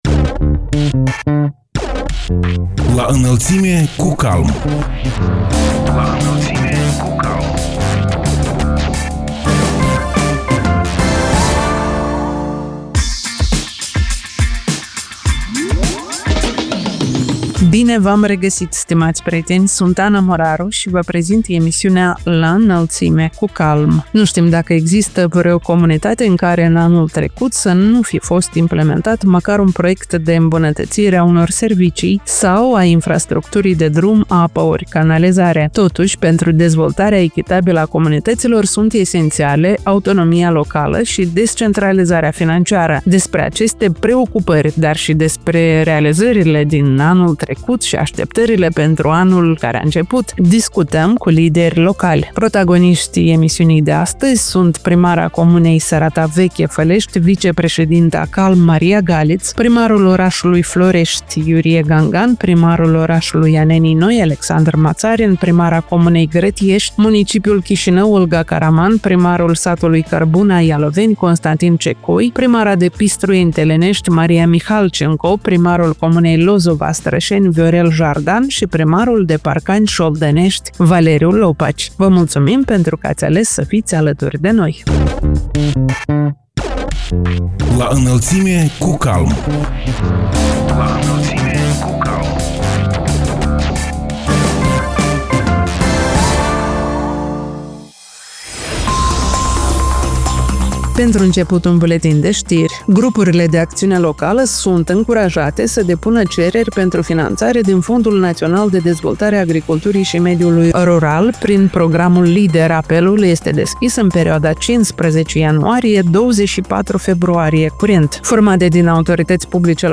Despre aceste preocupări, dar și despre realizările din anul 2025 și așteptările pentru anul 2026, discutăm cu lideri locali în cadrul emisiunii „La Înălțime cu CALM”.